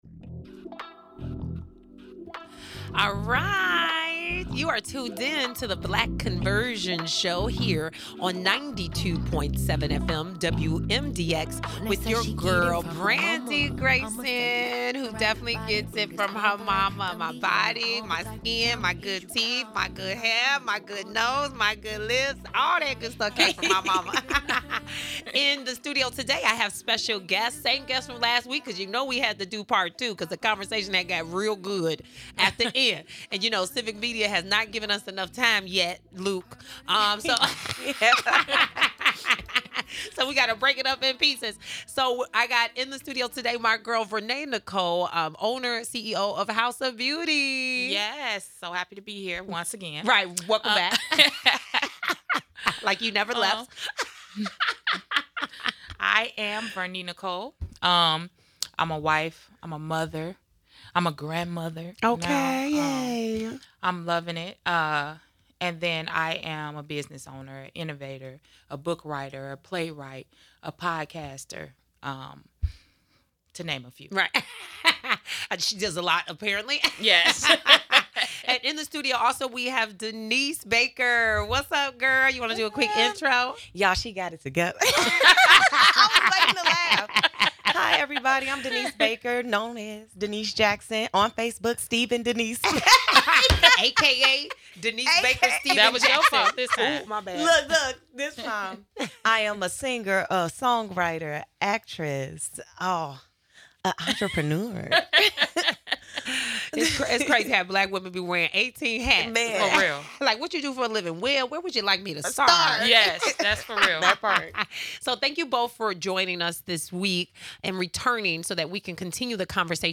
The three check in with how they are doing and what is going on in their lives. The conversations includes relationships and local tea you're not going to want to miss.